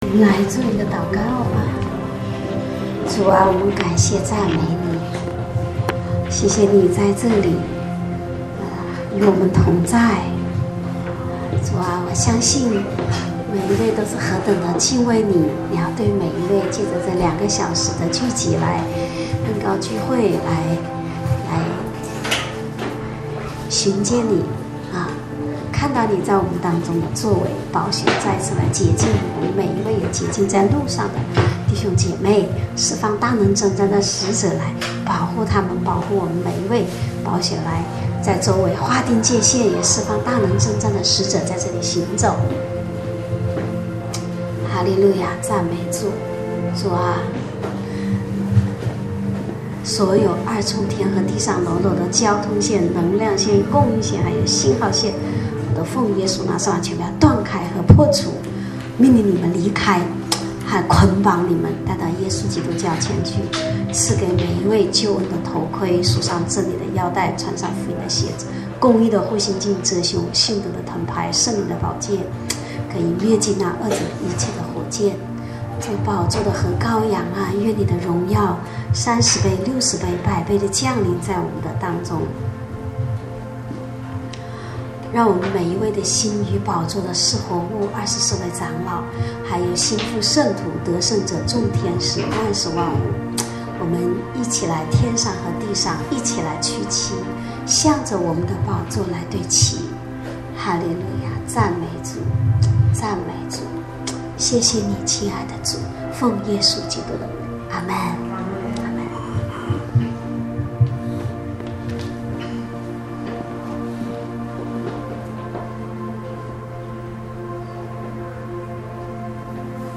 正在播放：--主日恩膏聚会录音（2014-12-14）